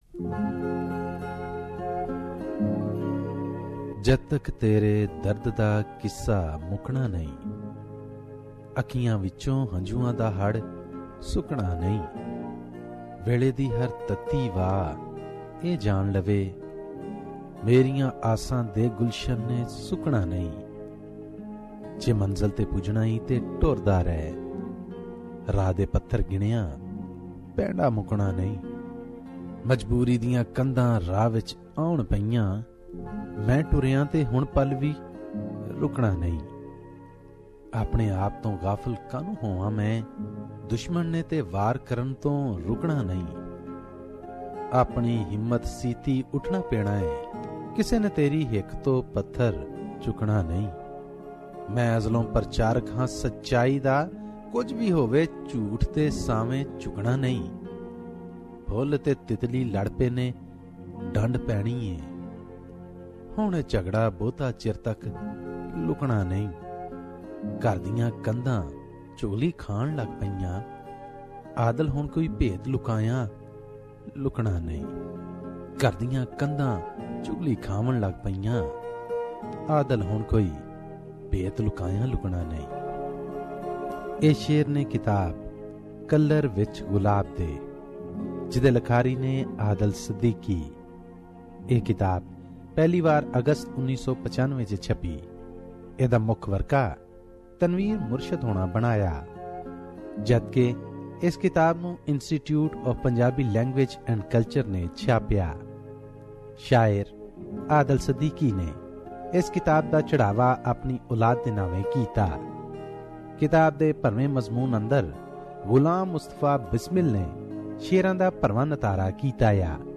The pen sheds tears : a book review